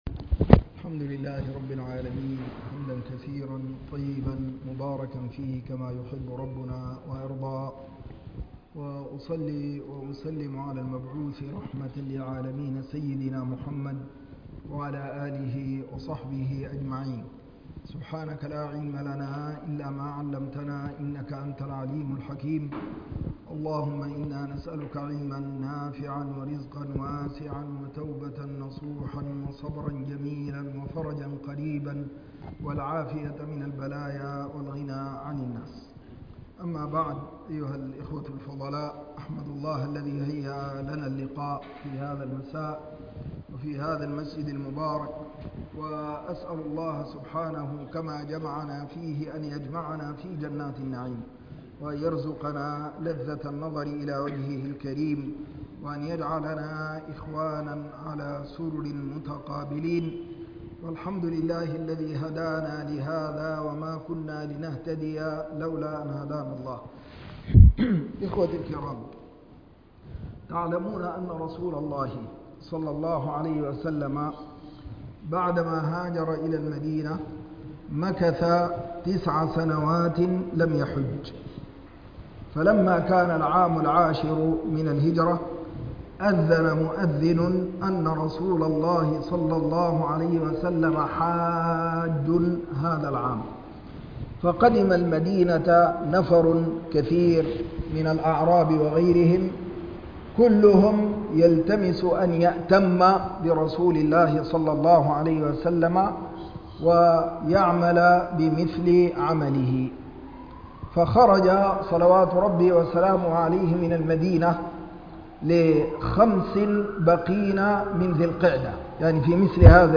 محاضرة عامة الحج نموذج التسليم المطلق